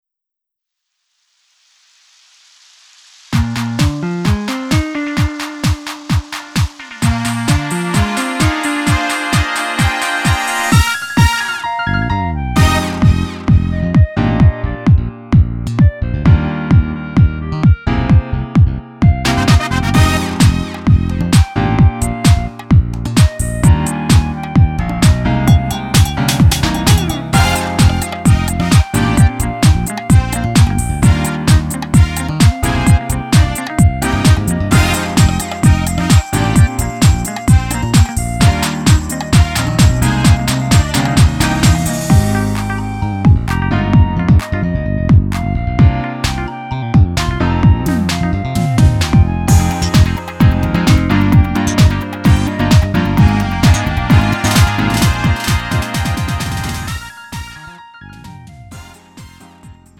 음정 원키 3:11
장르 가요 구분 Lite MR